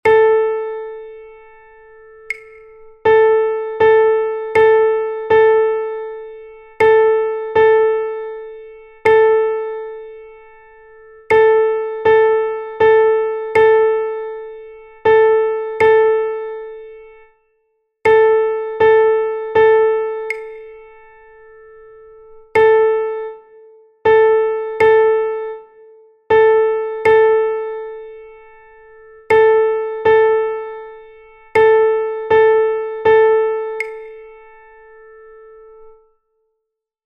Rhythmic dictation
DICTADO_RITMICO.mp3